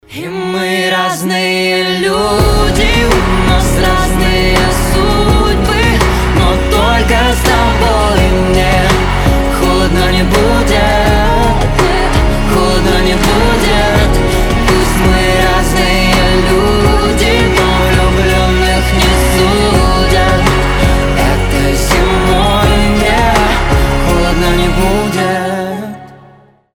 • Качество: 320, Stereo
дуэт
медленные